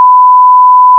1000hz.wav